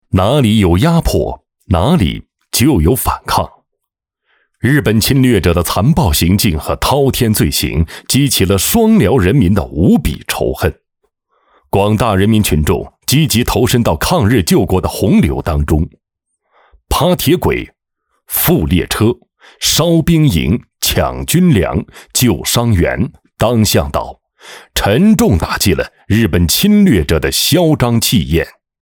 199男-【颁奖】最美退役军人-飞乐传媒官网